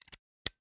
Lock Pad Lock Combination Sound
household